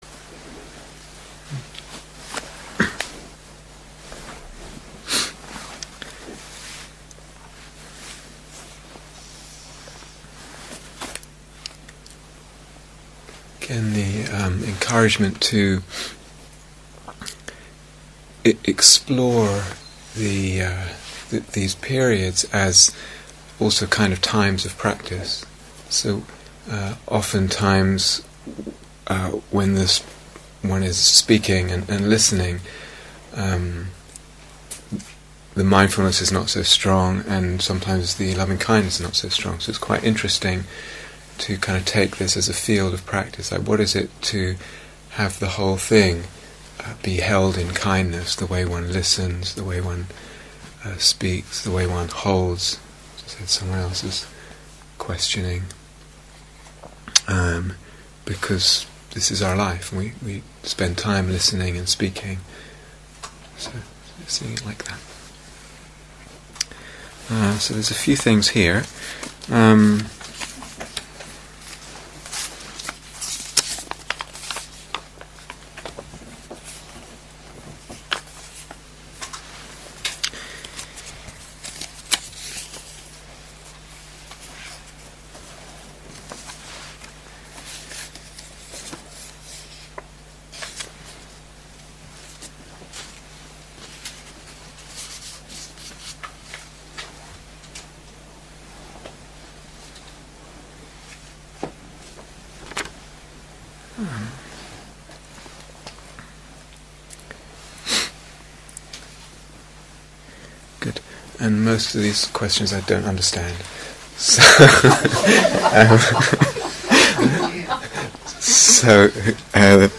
Question and Answer Session 2